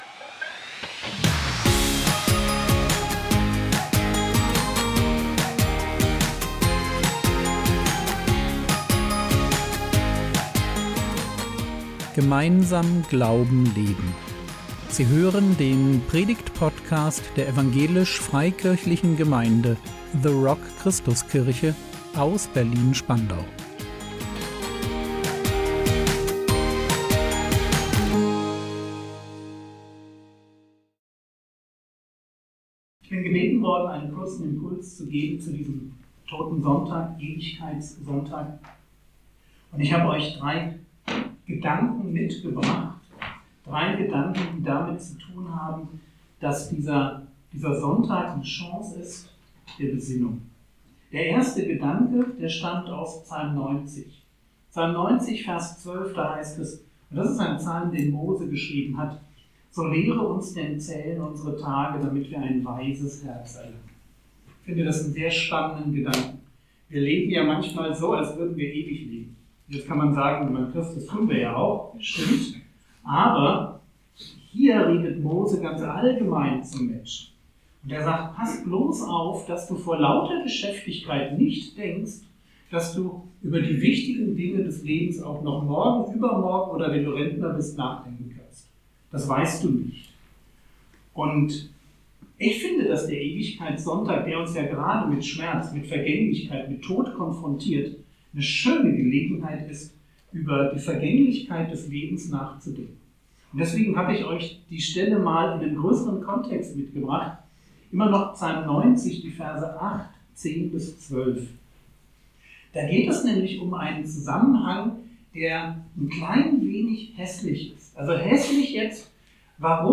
Gedanken zum Totensonntag | 24.11.2024 ~ Predigt Podcast der EFG The Rock Christuskirche Berlin Podcast